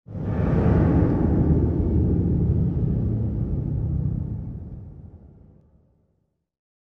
mixed-ghost-voices